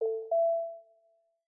Knock Notification 10.wav